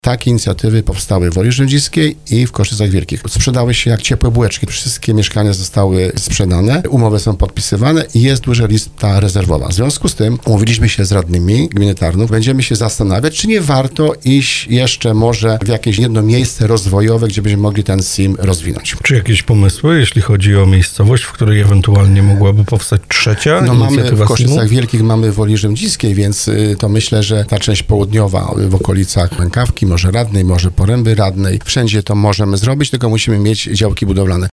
W rozmowie Słowo za Słowo wójt Grzegorz Kozioł tłumaczył, że gmina chce opierać swój rozwój na liczbie ludności i przychodach z tytułu PIT-u, zamiast na strefach przemysłowych czy dużych przedsiębiorcach, mogących powodować utrudnienia dla mieszkańców.